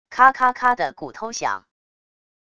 咔咔咔的骨头响wav音频